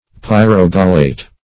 Search Result for " pyrogallate" : The Collaborative International Dictionary of English v.0.48: Pyrogallate \Pyr`o*gal"late\, n. (Chem.) A salt of pyrogallic acid; an ether of pyrogallol.